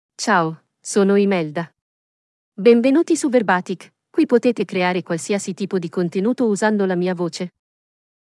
ImeldaFemale Italian AI voice
Imelda is a female AI voice for Italian (Italy).
Voice sample
Female